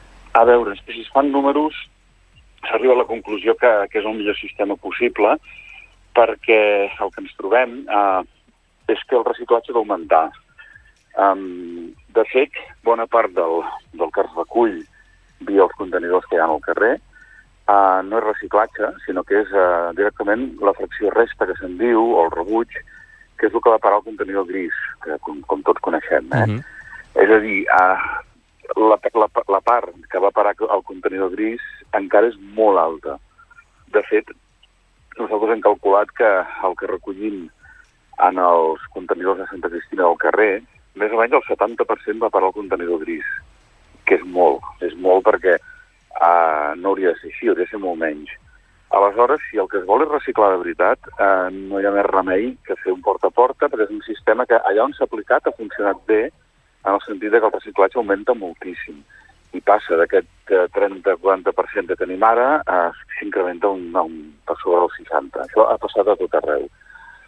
Entrevistes SupermatíNotícies
Per entendre com funcionarà aquest servei ens ha visitat al Supermatí el regidor de medi ambient del consistori de Santa Cristina d’Aro, David Segarra.